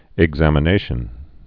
(ĭg-zămə-nāshən)